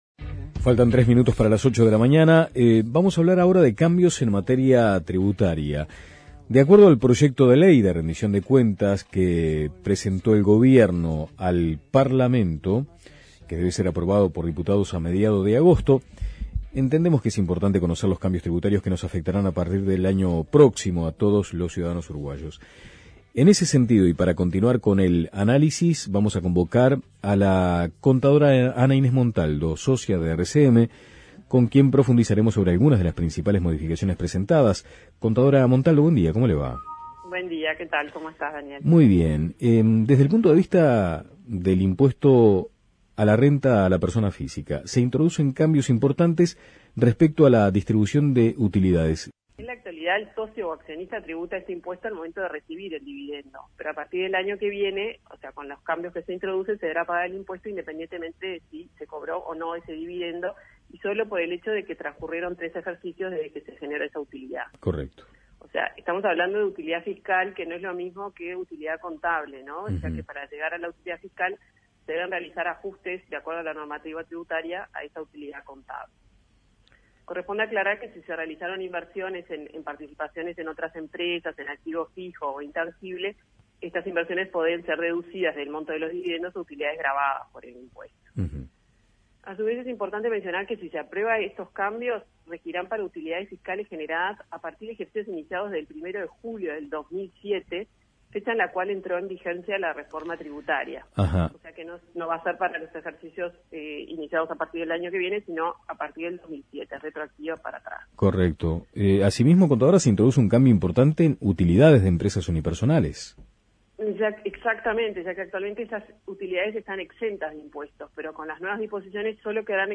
¿Cuáles son?